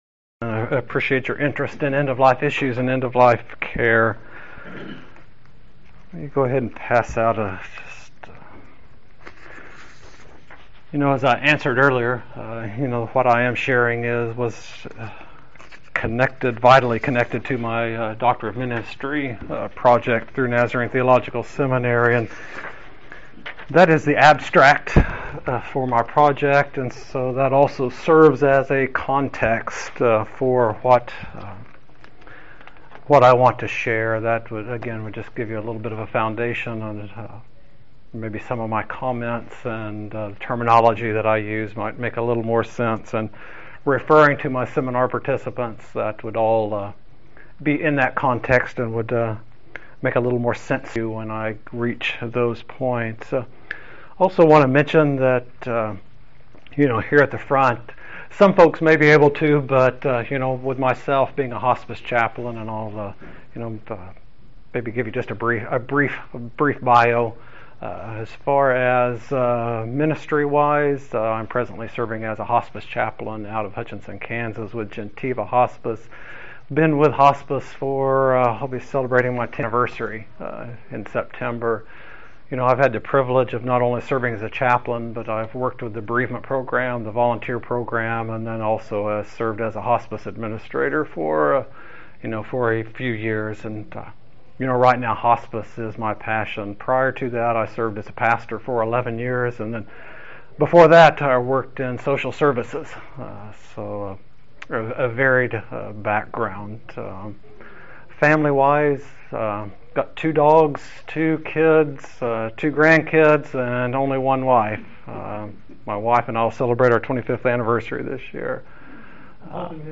These related topics will be covered in this important workshop.